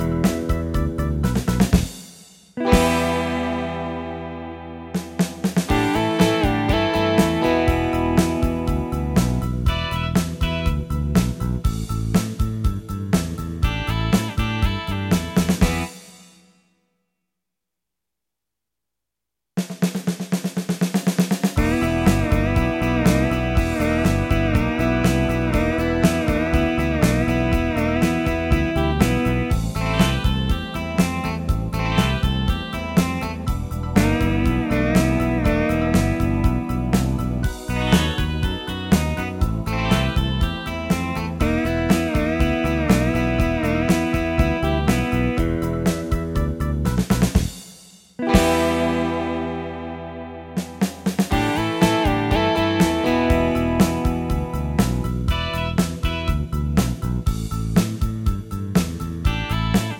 Instrumental MP3 version